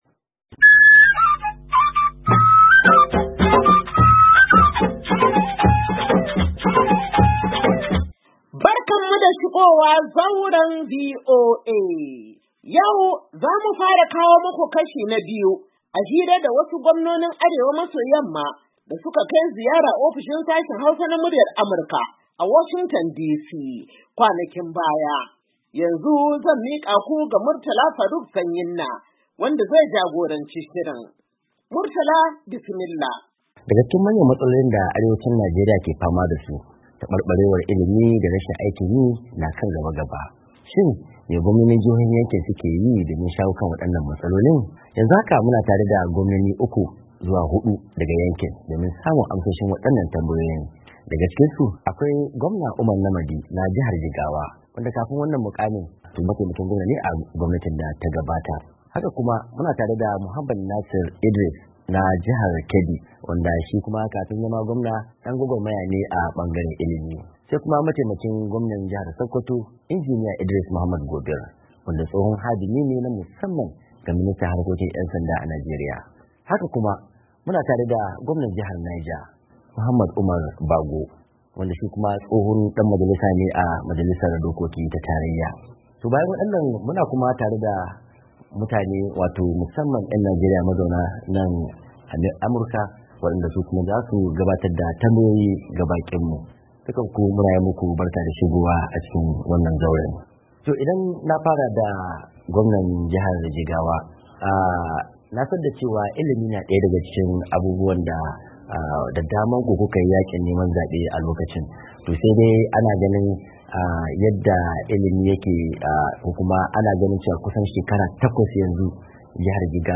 Shirin Zauren VOA na wannan makon zai kawo muku tattaunawa da wasu daga cikin Gwamnoni Jihohin Arewa Maso Yamma da suka kai ziyara Ofishin Sashin Hausa na Muryar Amurka a Washington DC.